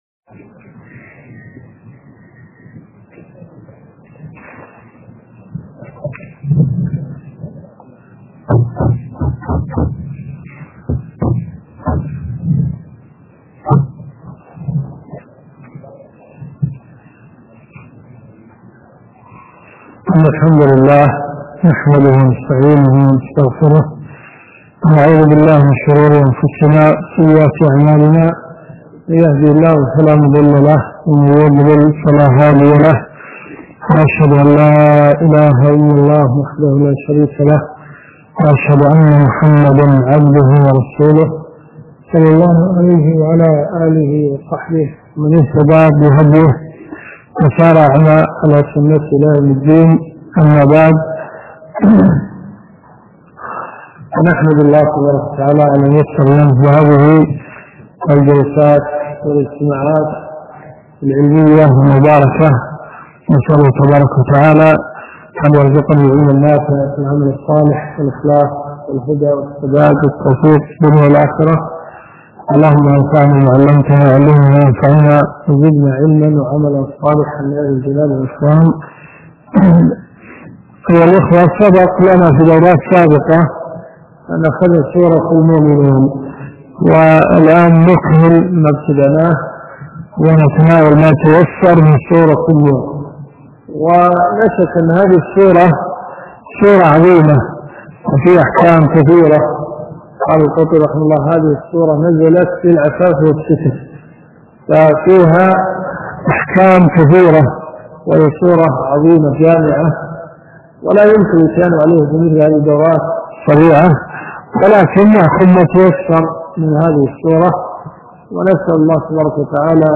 الرئيسية الدورات الشرعية [ قسم التفسير ] > تفسير سورة النور . 1434 .